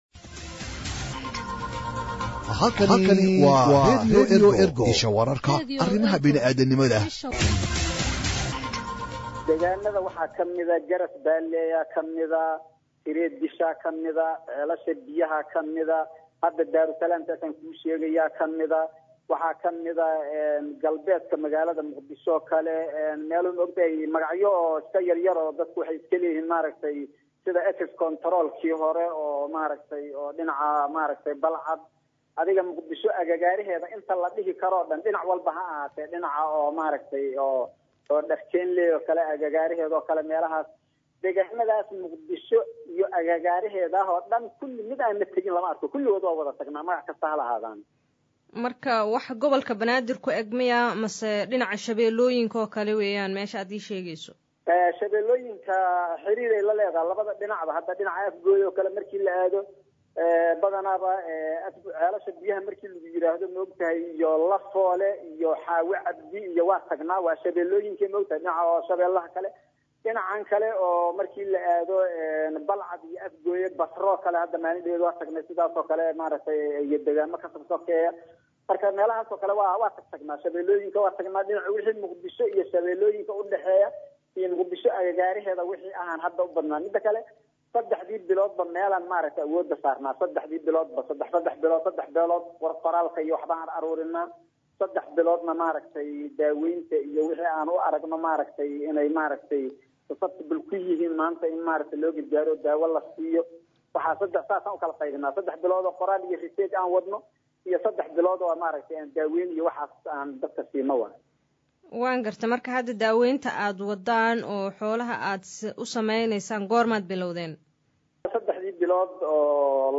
WAREYSI-DHAKHTAR-XOOLO.mp3